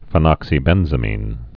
(fĭ-nŏksē-bĕnzə-mēn)